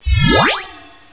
beam2.wav